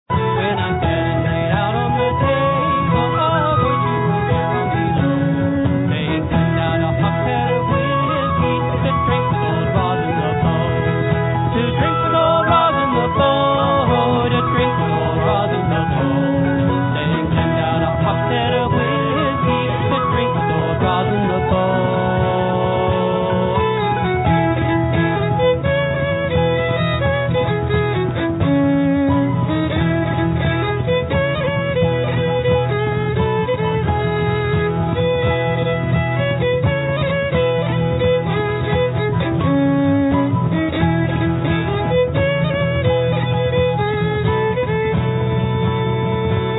Traditional Irish/Celtic music
Lead Vocal, Bodhràn
Fiddle
Guitar, Backup Vocal
Mandolin
Soprano B Whistle